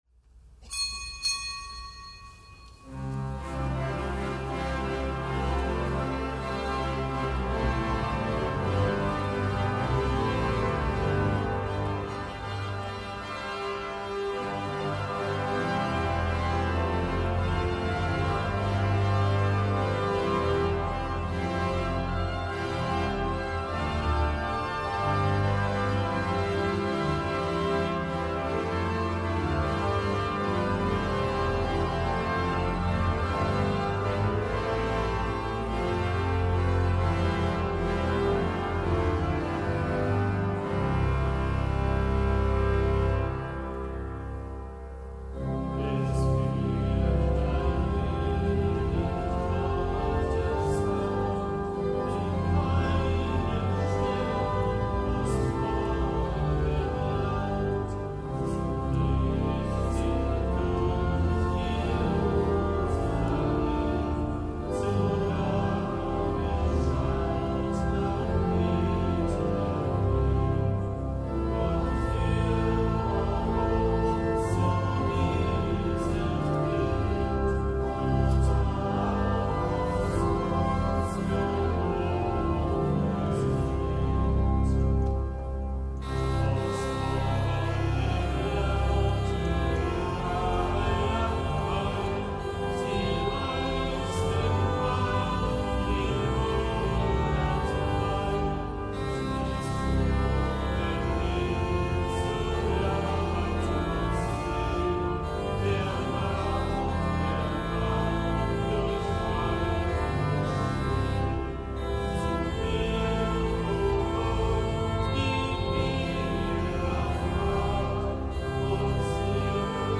Kapitelsmesse aus dem Kölner Dom am Mittwoch der sechzehnten Woche im Jahreskreis, dem nicht gebotenen Gedenktag des heiligen Apollinaris, Bischof von Ravenna, Märtyrer.